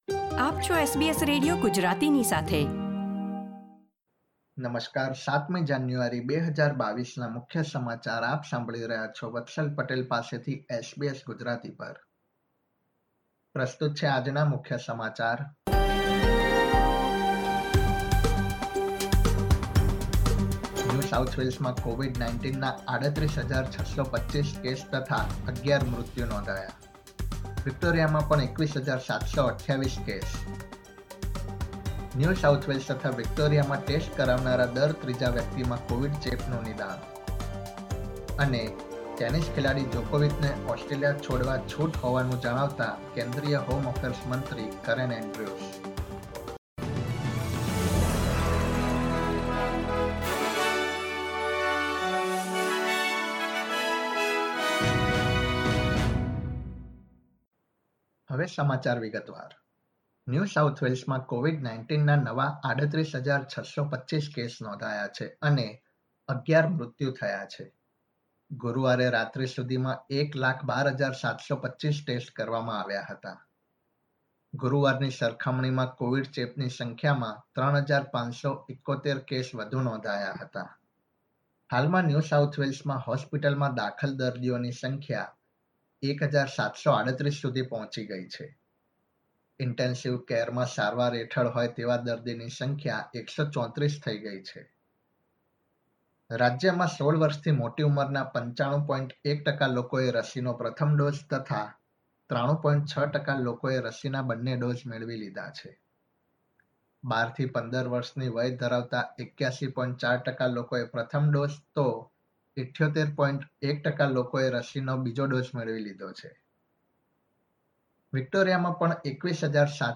SBS Gujarati News Bulletin 7 January 2022